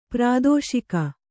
♪ prādōṣika